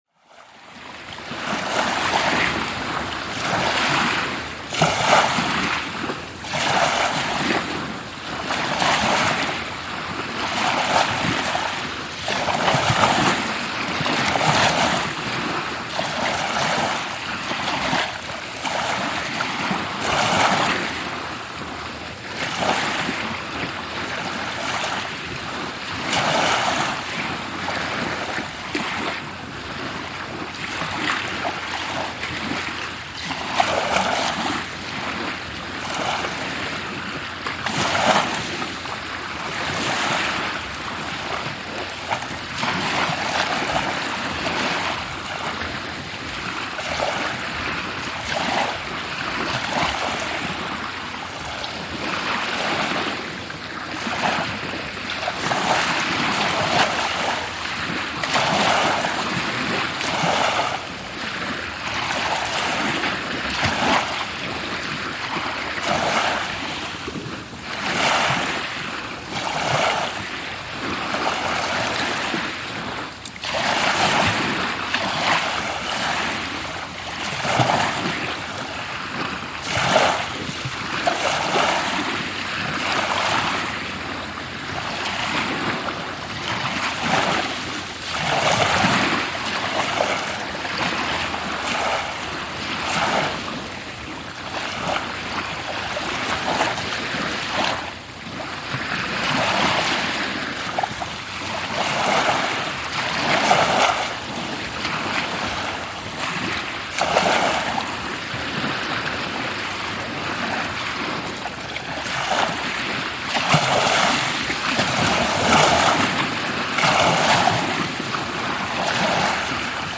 Sunset shoreline sounds
The sunsets on Malapascua Island in the Philippines feel like explosions in the sky — wild, breathtaking, and almost unreal.
But beneath that blaze of colour, it’s the sound of the waves that holds you — soft laps against the shoreline, repeating like a forgotten lullaby. I stayed there alone, wrapped in the rhythm of the sea, as if time had unraveled and tomorrow didn’t exist.